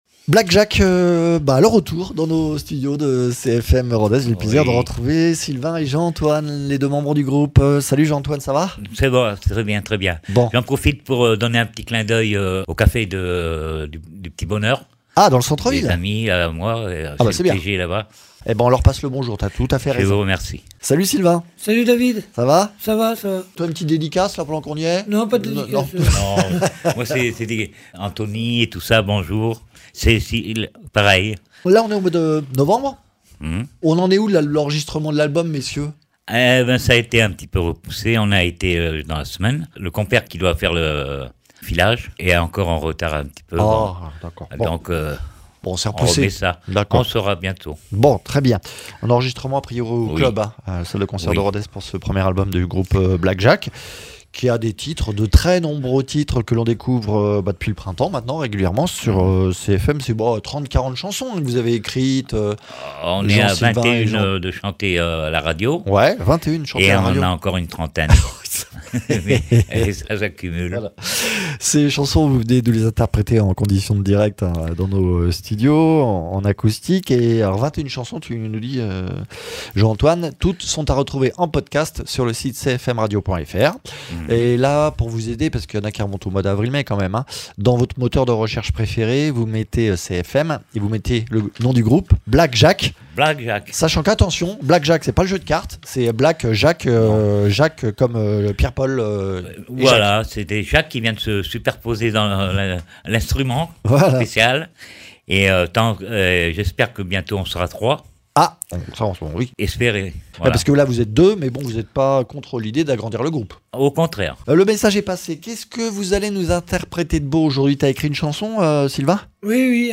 Le groupe nous joue en studio deux nouveaux titres